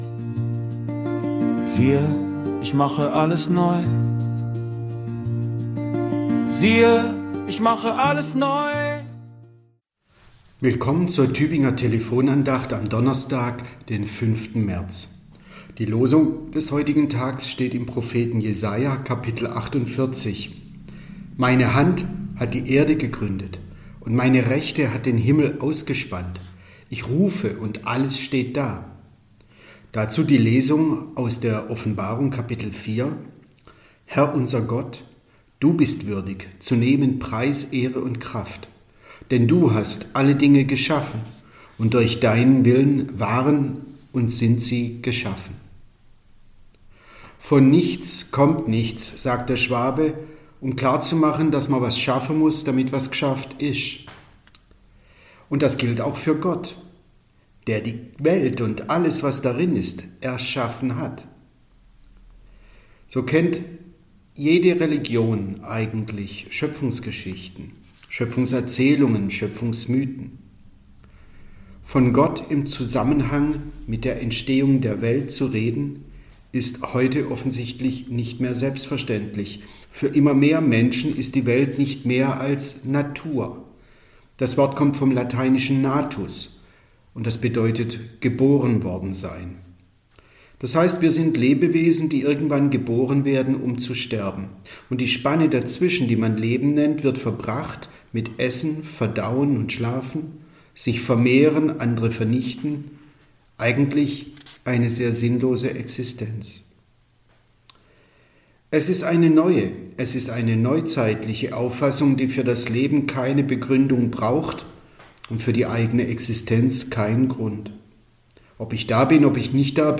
Andacht zum Wochenspruch